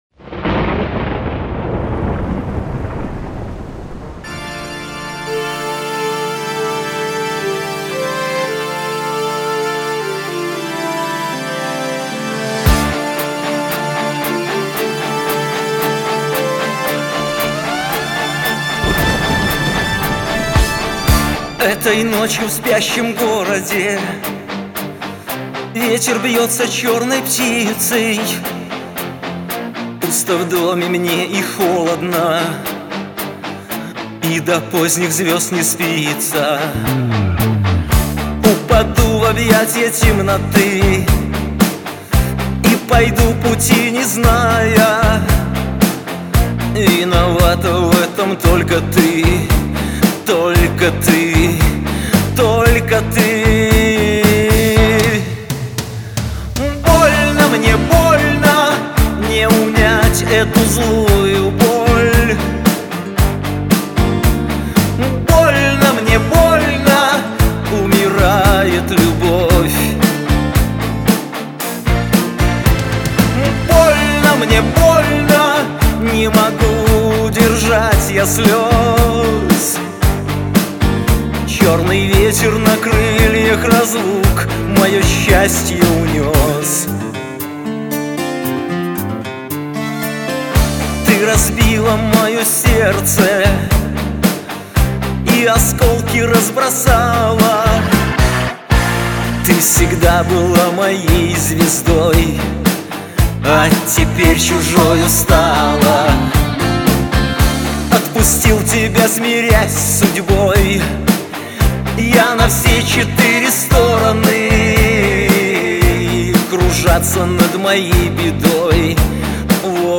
Всі мінусовки жанру Pop-UA
Плюсовий запис